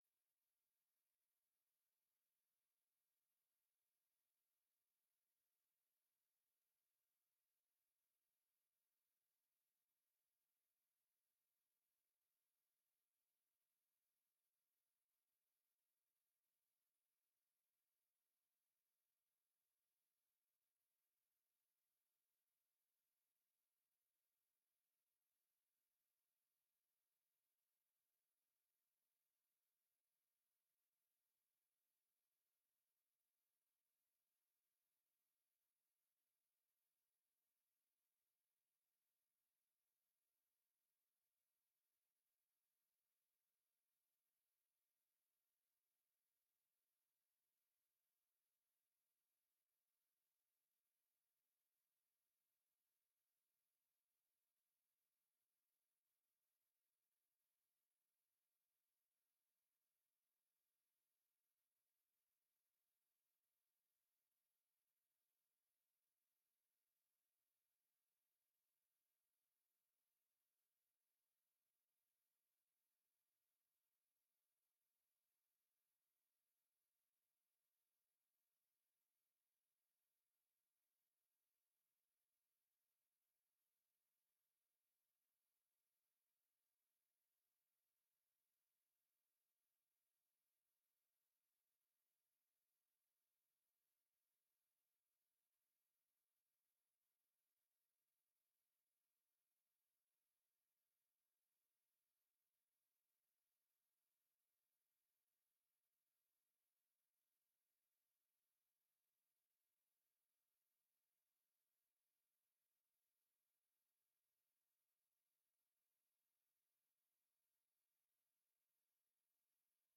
Download de volledige audio van deze vergadering
Locatie: DRU Industriepark - Conferentiezaal